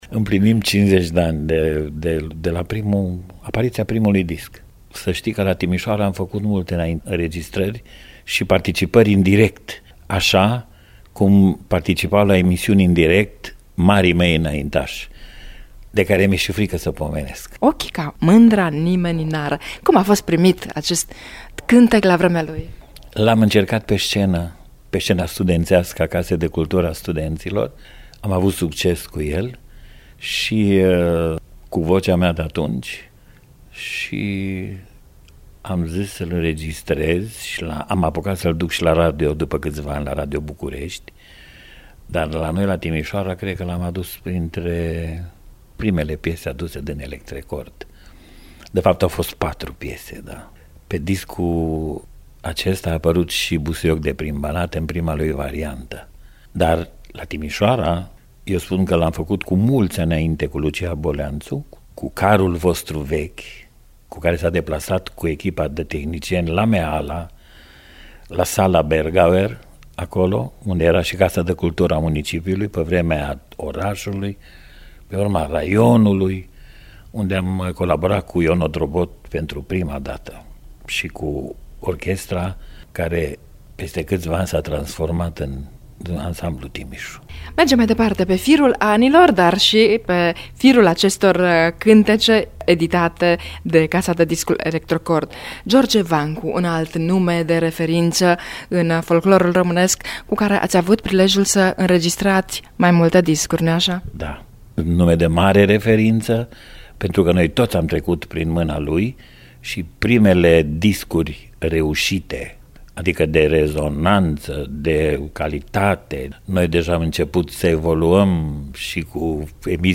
Fragment din interviul realizat de